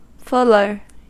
Ääntäminen
Ääntäminen US Tuntematon aksentti: IPA : /ˈfʊl.ə(ɹ)/ Haettu sana löytyi näillä lähdekielillä: englanti Käännös Substantiivit 1. huovuttaja 2. veriura Fuller on sanan full komparatiivi.